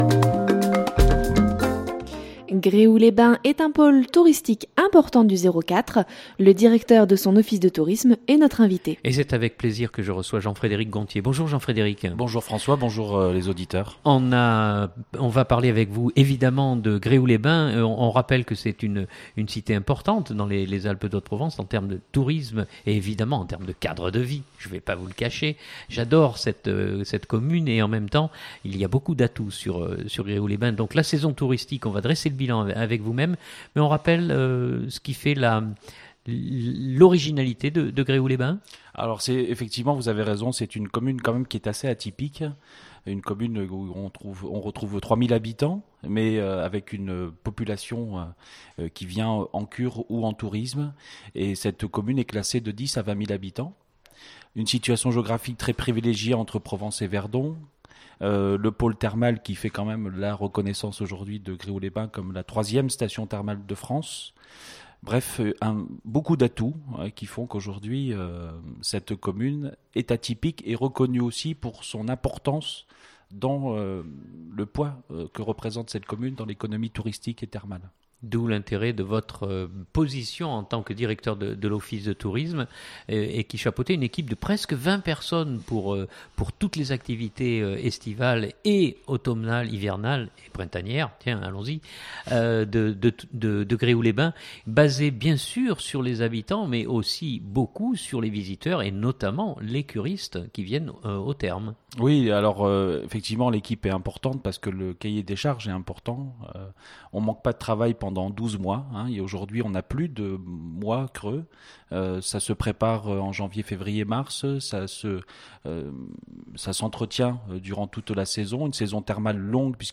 Fréquentations, curistes, visiteurs étrangers, notamment chinois, développement durable mais aussi l'avenir sur le plan intercommunal des offices de tourisme de l'agglomération sont au menu de cette interview.